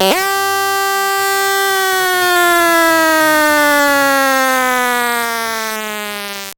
VEC3 FX Alarm 08.wav